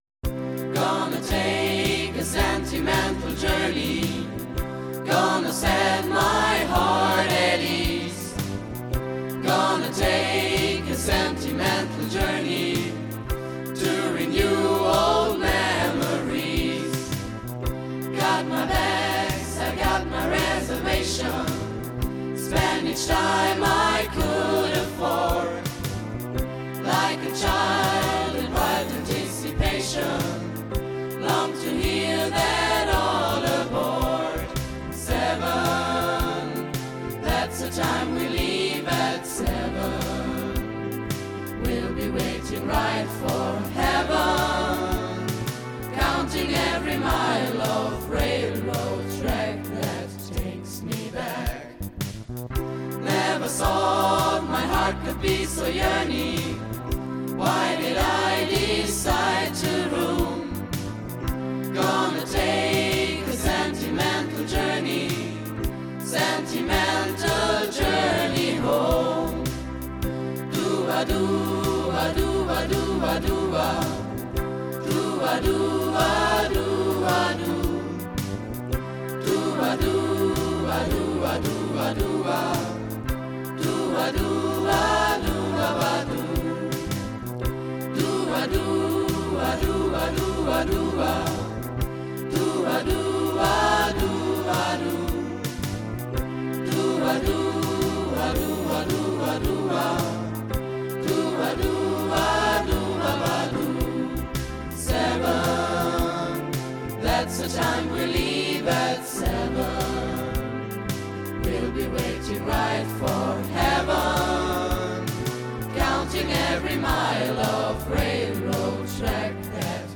Erinnerungen an eine Chor-CD
„DIE ERNST-REUTER-SCHULE HAT EINEN TOLLEN CHOR“ hat sich